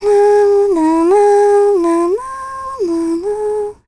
Ophelia-vox-Hum.wav